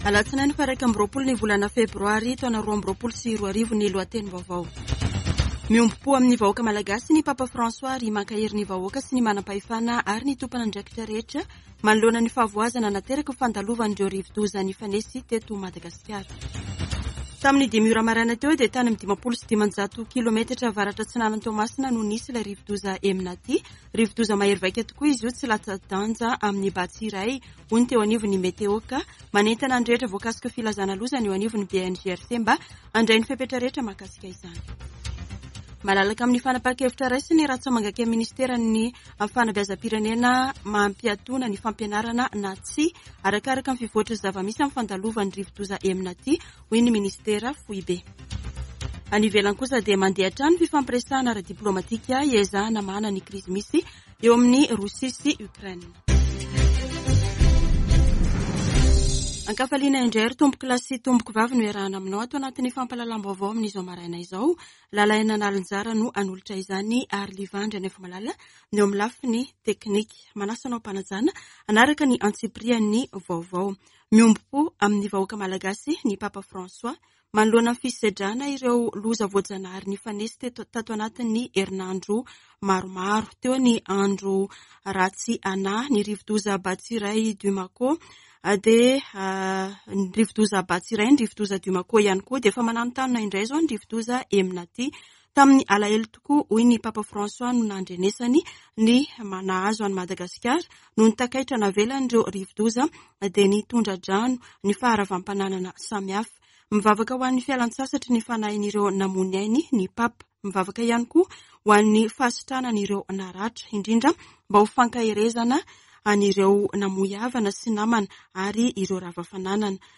[Vaovao maraina] Alatsinainy 21 febroary 2021